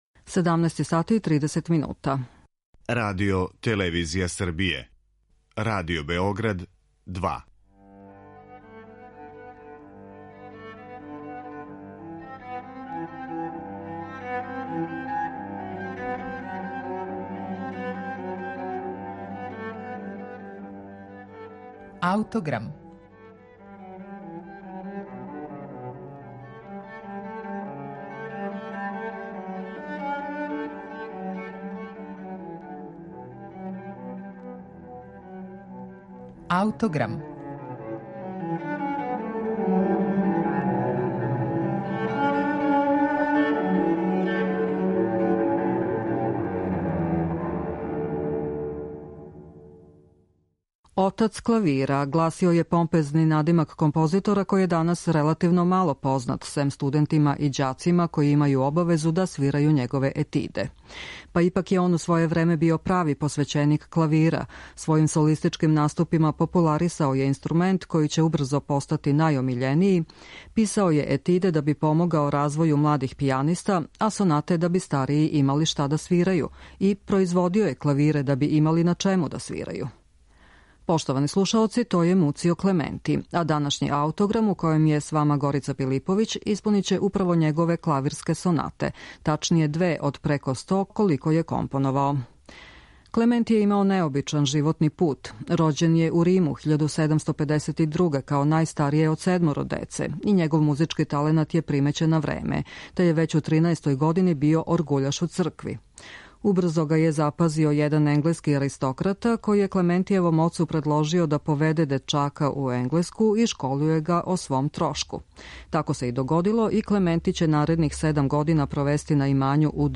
клавирске сонате